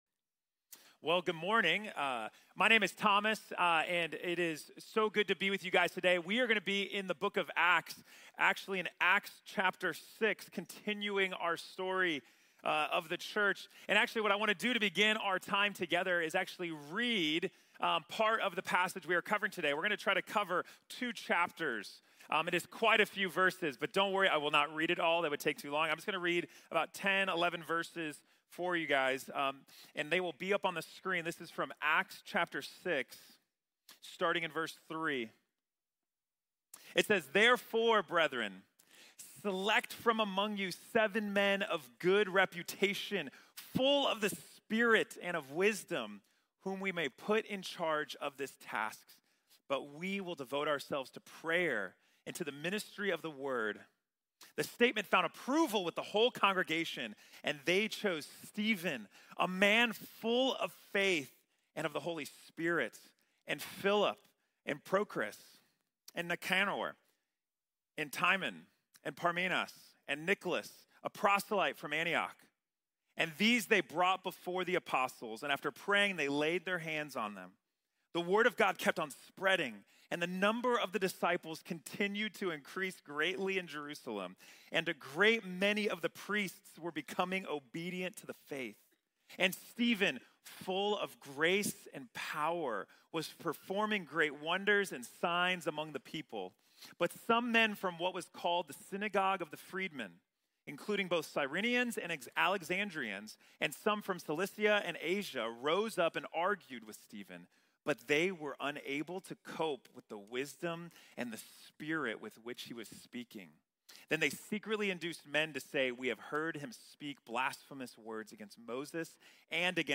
Character Under Pressure | Sermon | Grace Bible Church